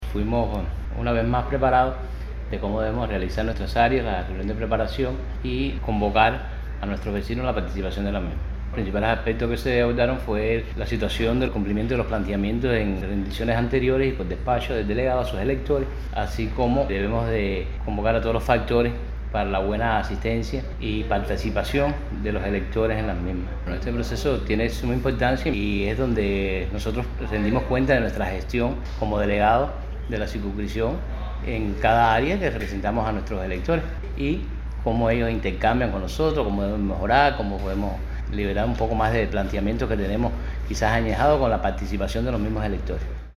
Así lo manifestó Rubén Díaz Suárez, delegado de la circunscripción 38 de Pedro Betancourt y presidente del Consejo Popular Guira de Macuriges: